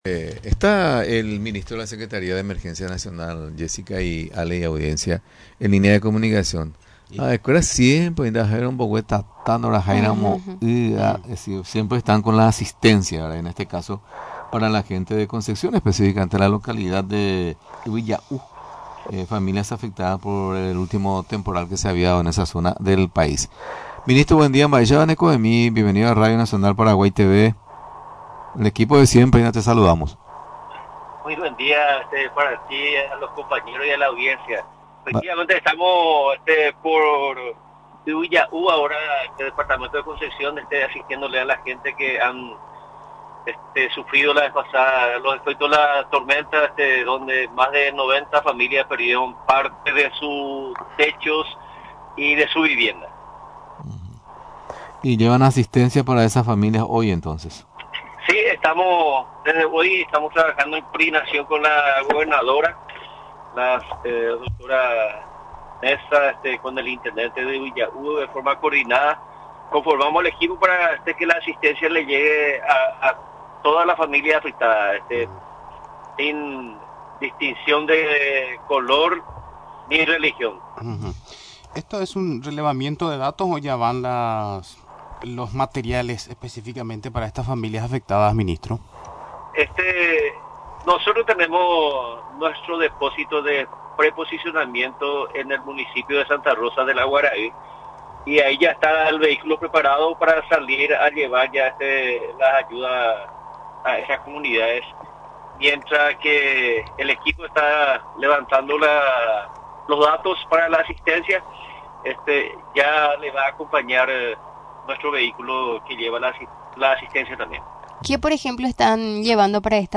El apoyo de la SEN, se realiza luego de las tormentas registradas días pasados en la zona, que dejó a unas 90 familias damnificadas, explicó este martes el ministro Arsenio Zárate.